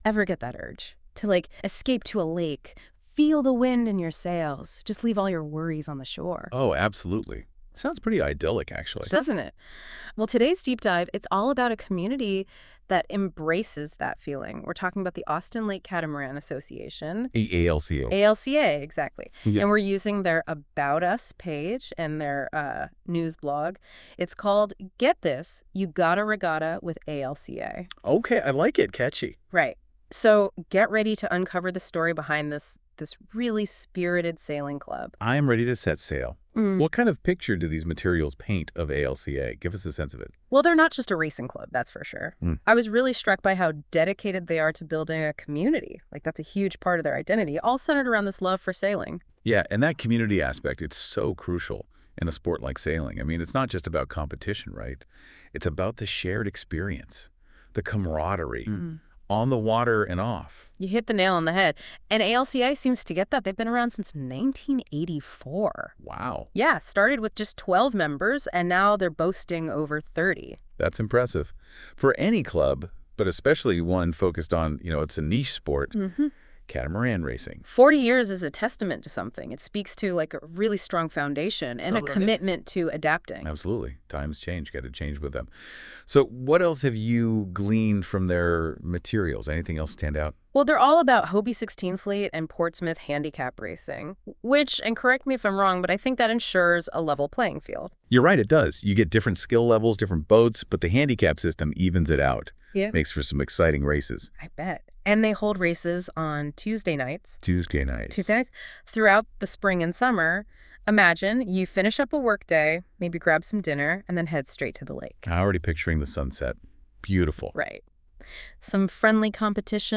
Alca-AI-Promo.wav